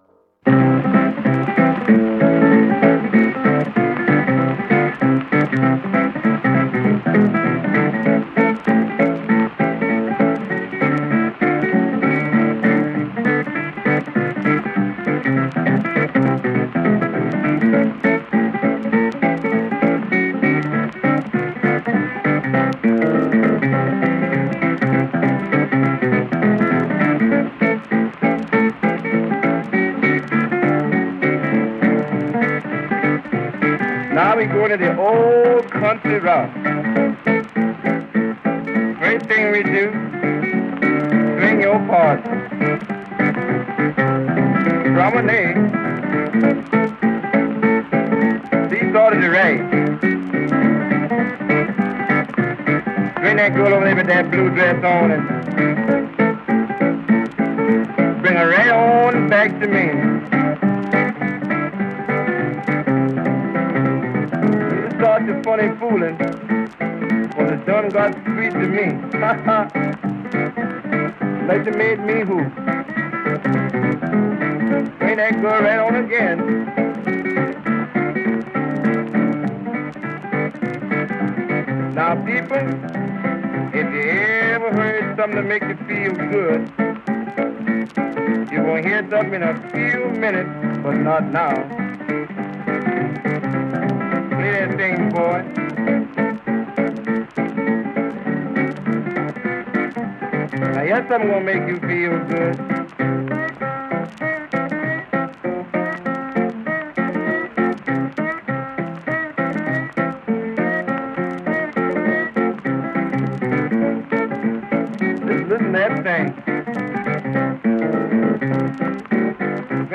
out-of-copyright early blues, jazz and folk recordings